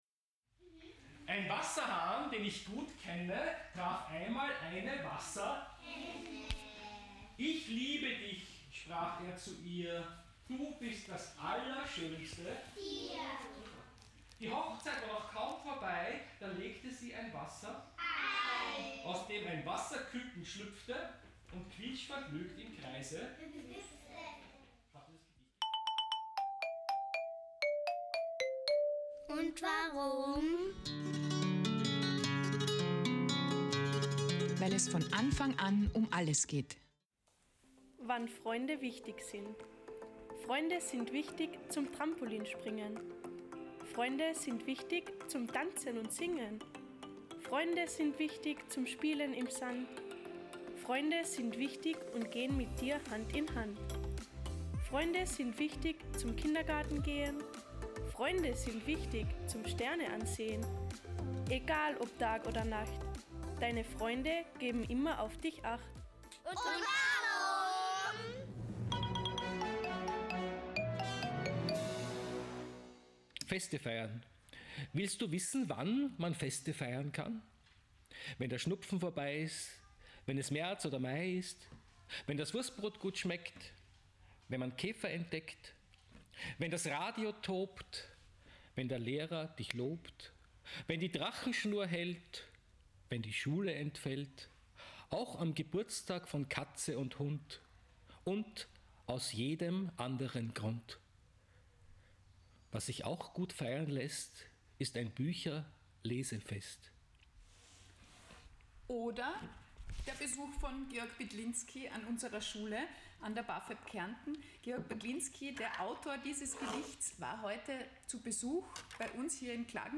Kinderliteratur beflügelt die FAntasie und fördert das Sprachgefühl. Kinderbuchautor Georg Bydlinski im Interview.